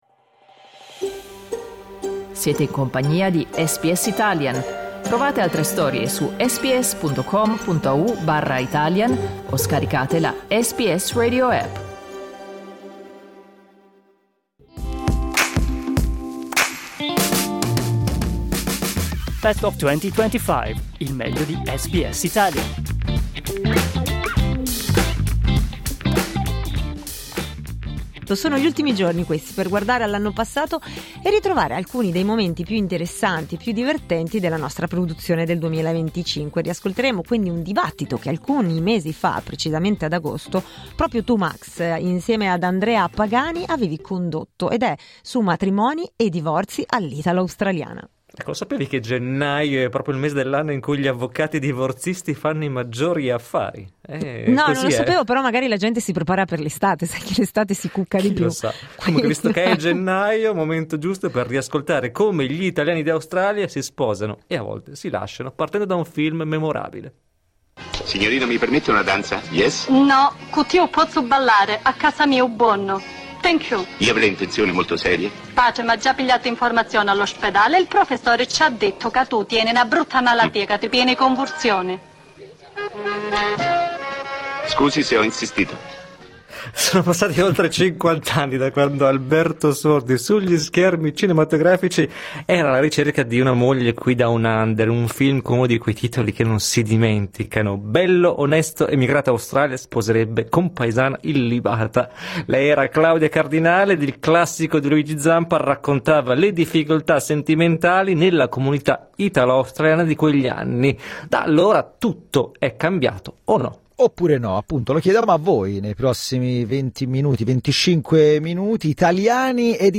In questo talkback dello scorso anno, abbiamo chiesto agli italiani d'Australia di condividere la propria esperienza in tema di matrimoni e divorzi.